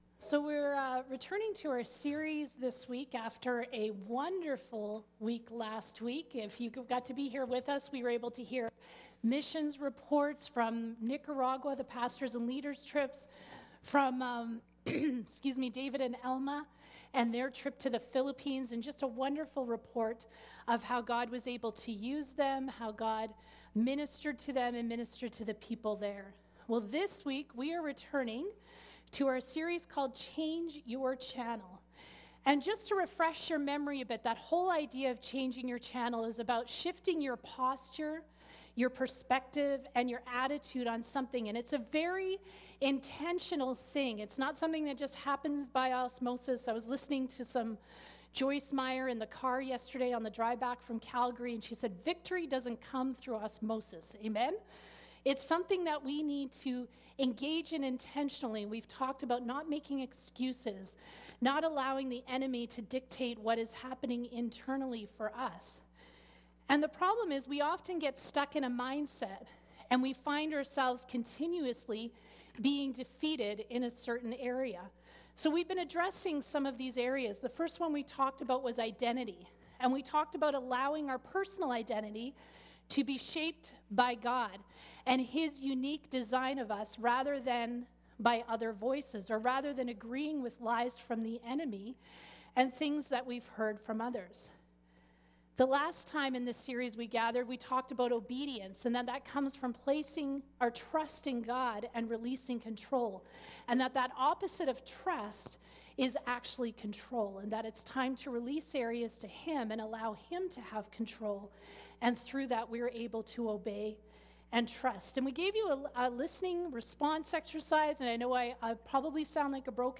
Colossians 3:13 Service Type: Sunday Service MP3 will be added by Wednesday.